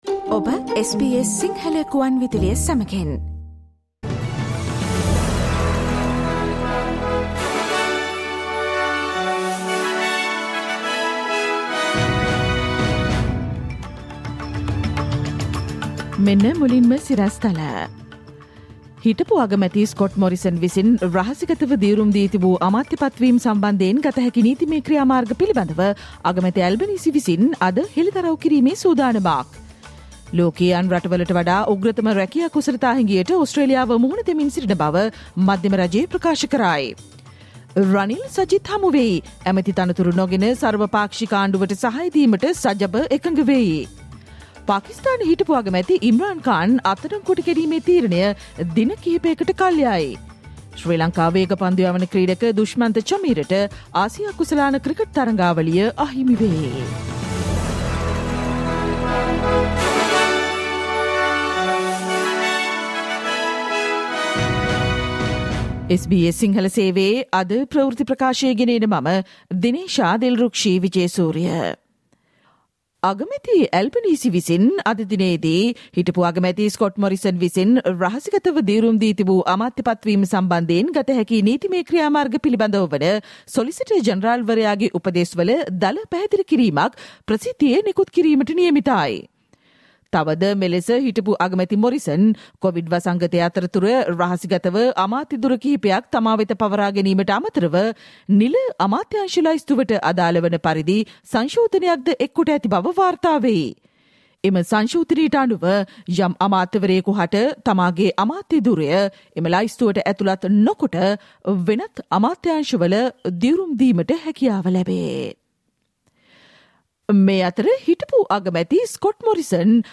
Listen to SBS Sinhala Radio news bulletin on Tuesday 23 August 2022